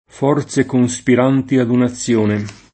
cospirare
cospirare v.; cospiro [ ko S p & ro ] — lett. conspirare : conspiro [ kon S p & ro ]: forze conspiranti ad un’azione [ f 0 r Z e kon S pir # nti ad un a ZZL1 ne ] (Carducci) — sim. alternanza nella voce in -zione